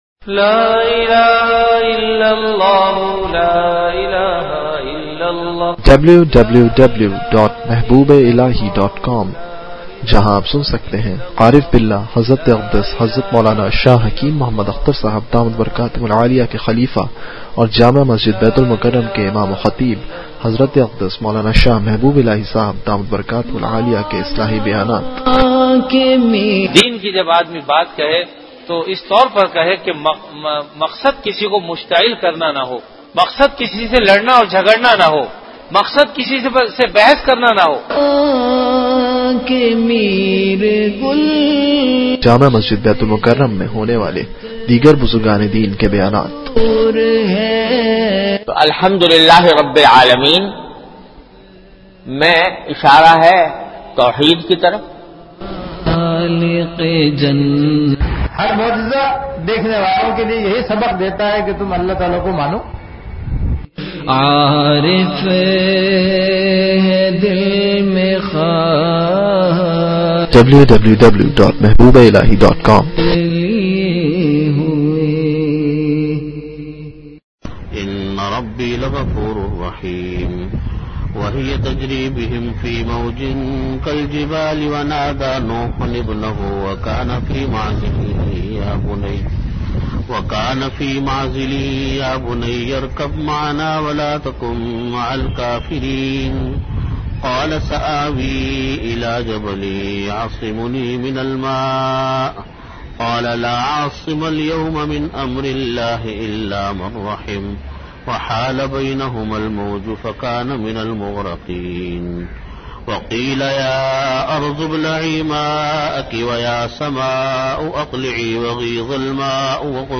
Delivered at Jamia Masjid Bait-ul-Mukkaram, Karachi.
Tafseer Lectures Comments & Discussion Please enable JavaScript to view the comments powered by Disqus.